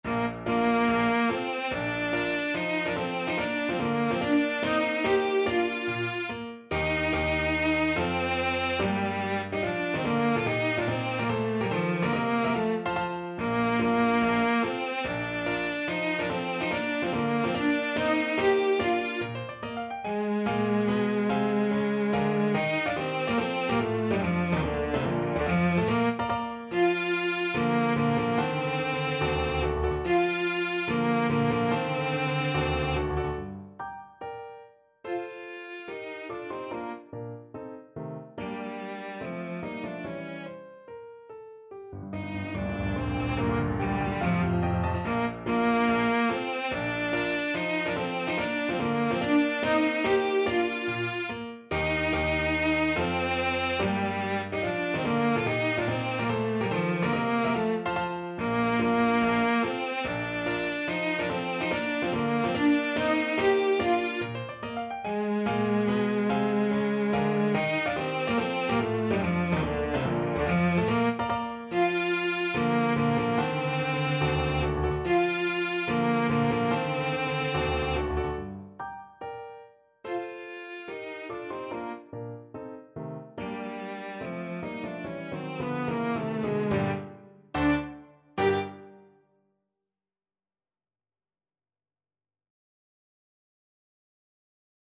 Cello
Allegro non troppo (=72) (View more music marked Allegro)
Bb major (Sounding Pitch) (View more Bb major Music for Cello )
Classical (View more Classical Cello Music)